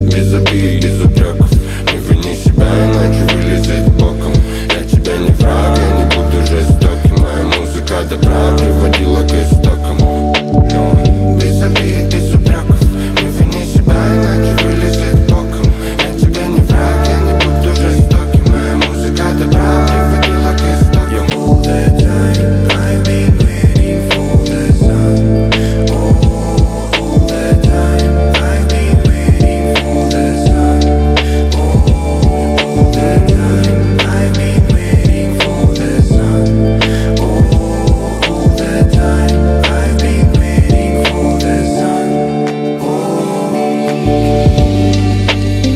• Качество: 320, Stereo
Хип-хоп
русский рэп
красивый мужской вокал